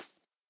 assets / minecraft / sounds / mob / rabbit / hop2.ogg
hop2.ogg